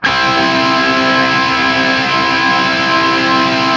TRIAD C  L-R.wav